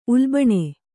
♪ ulbaṇe